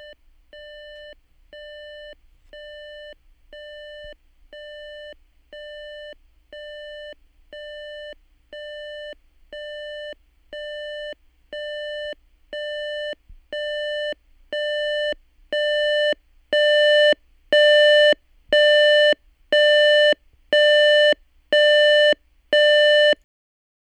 Attachments Recording of my digital alarm